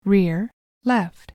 audio-channel-rear-left.mp3